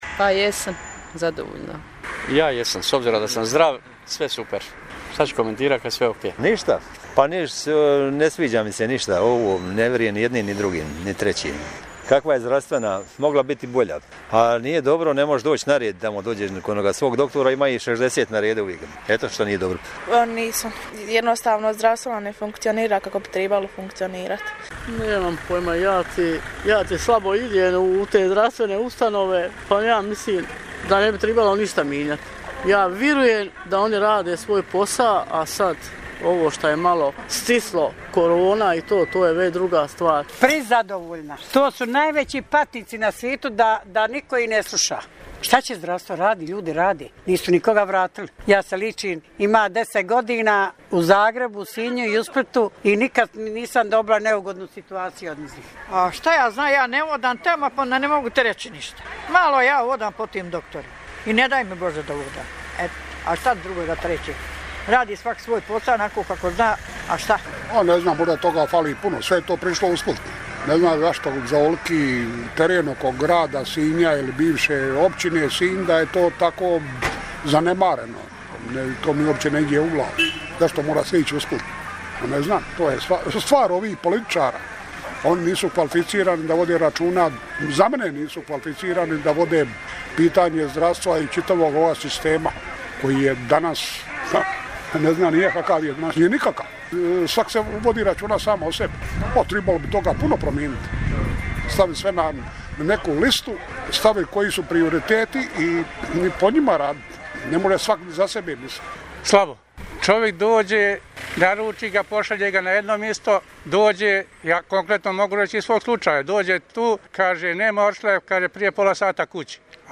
Poslušajte što kažu građani Sinja o kvaliteti zdravstvene skrbi na području Cetinske krajine:
anketa_zdravstvo.mp3